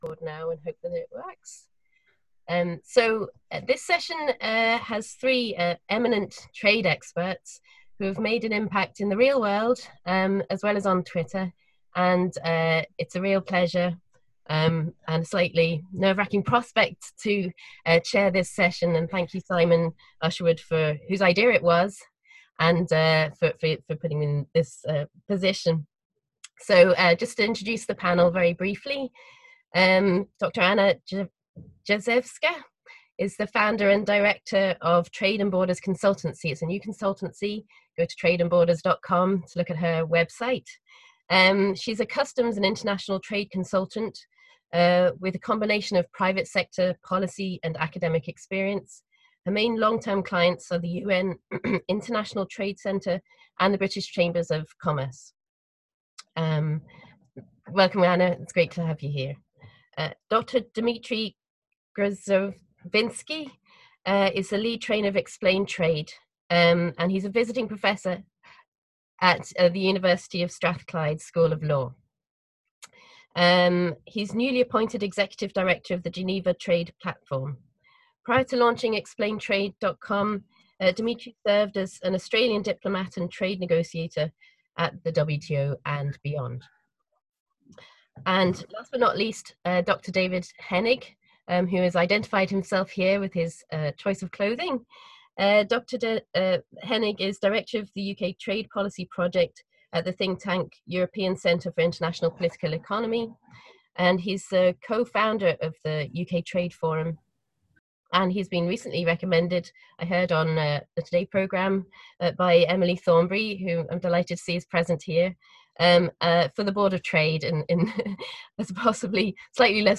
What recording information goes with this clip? Sadly, we weren’t able to welcome its 400 delegates to Belfast but have been convening panels online. One such panel was run jointly with Queen’s Policy Engagement, and below is an audio recording of the event.